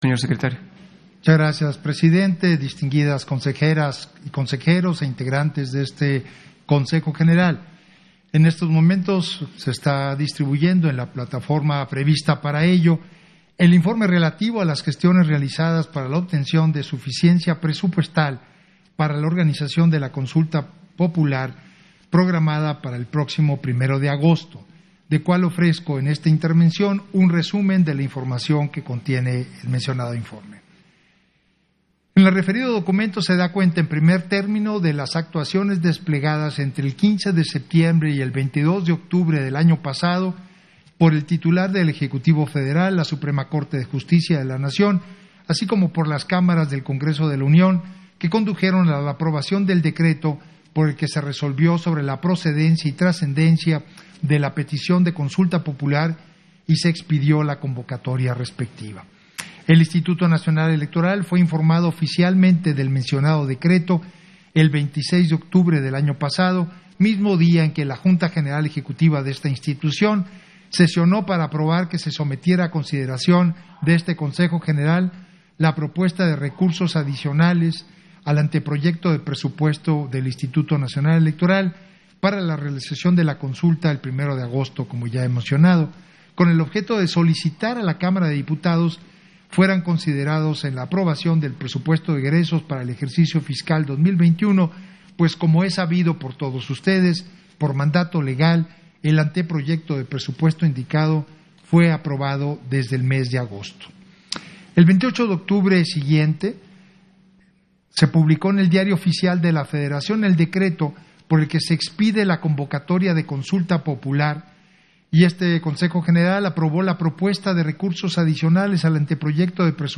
Intervención de Edmundo Jacobo Molina en Sesión Ordinaria, respectp al informe respecto a gestiones para la organización de la Consulta Popular